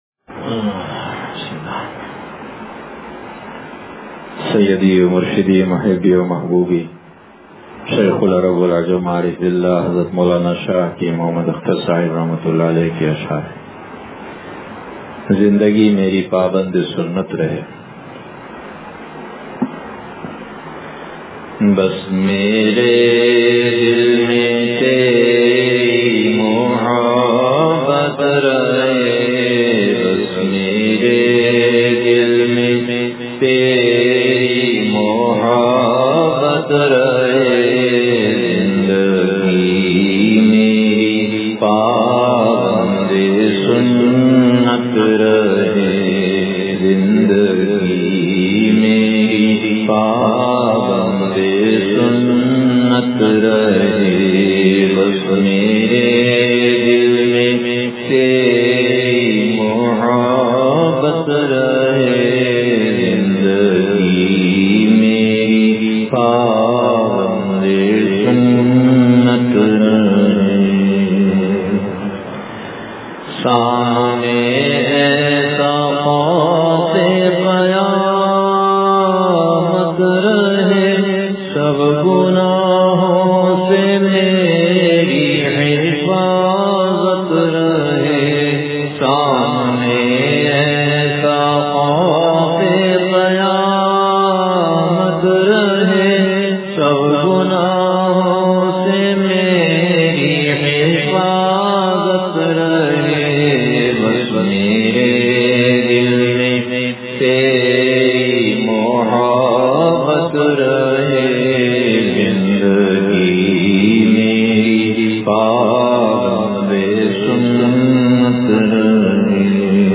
زندگی میری پابندِ سنت رہے – مجلس بروز بدھ